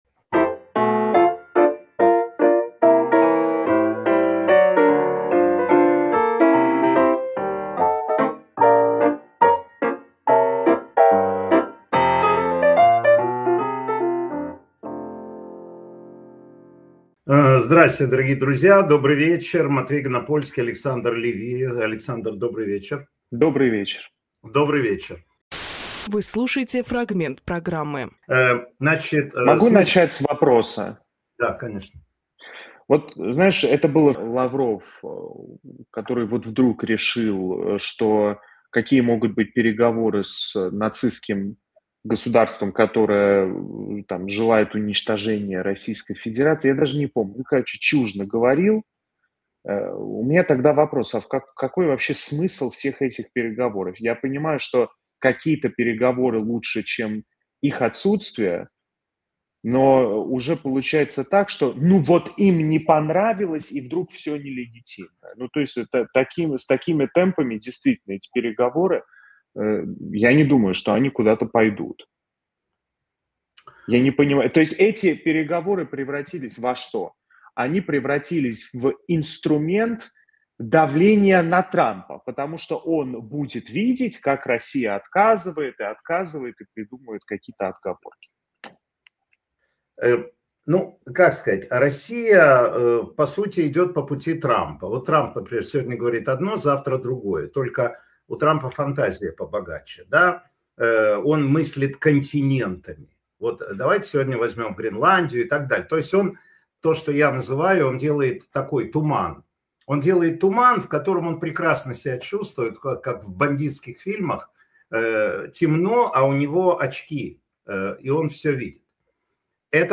Фрагмент эфира от 30.01.26